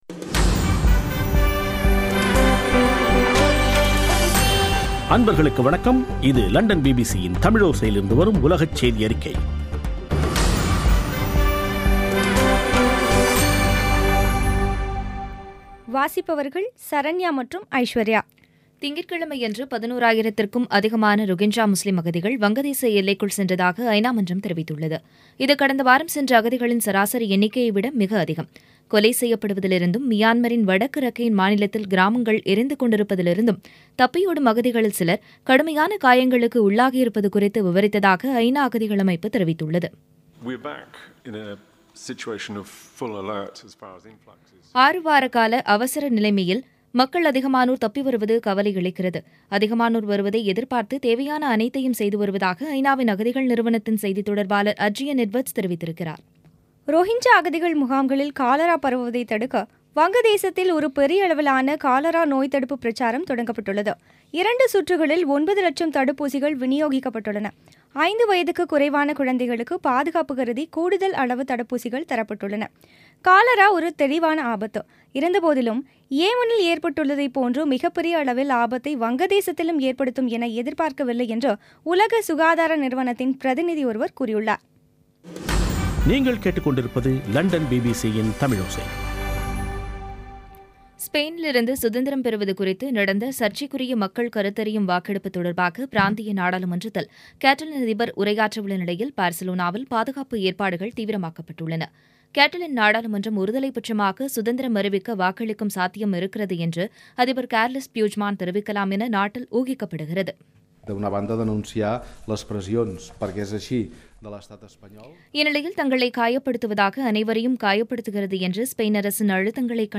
பிபிசி தமிழோசை செய்தியறிக்கை (10.10.2017)